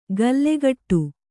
♪ gallegaṭṭu